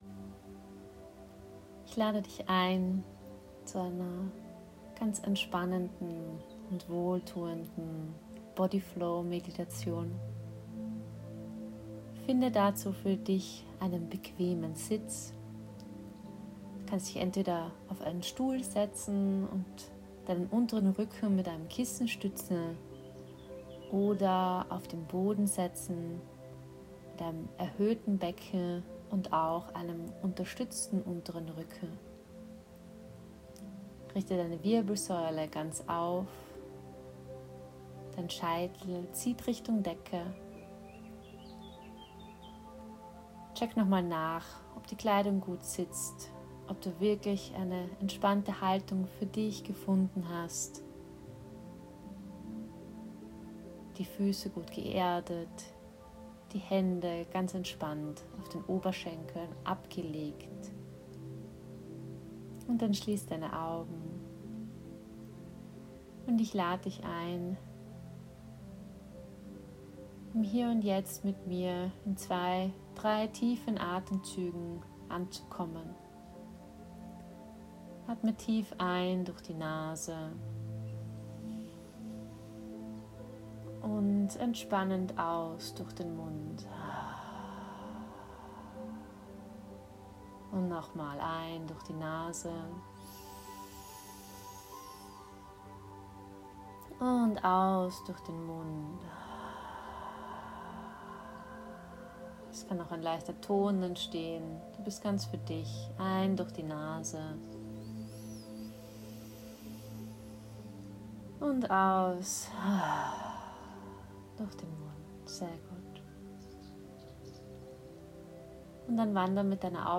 bodyflow-meditation.m4a